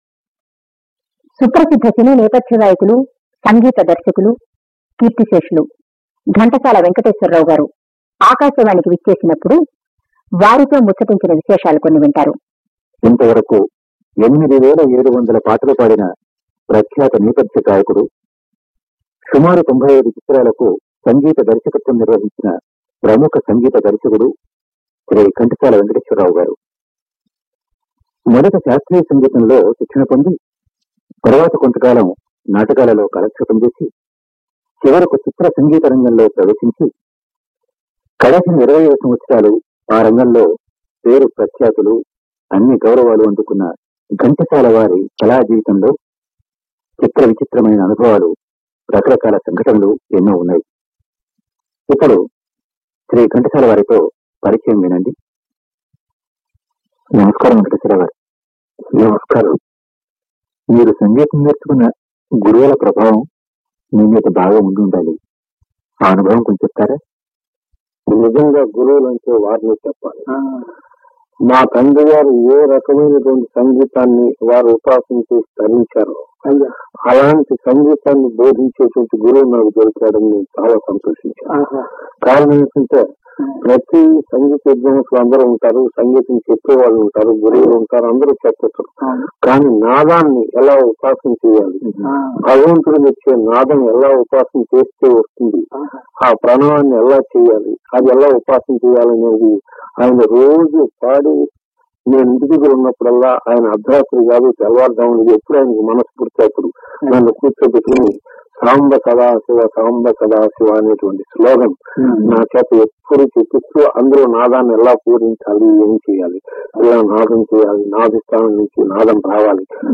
ఘంటసాల గారి ఇంటర్వూ